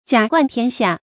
甲冠天下 jiǎ guàn tiān xià
甲冠天下发音